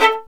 Index of /90_sSampleCDs/Roland L-CD702/VOL-1/STR_Violin 1-3vb/STR_Vln2 _ marc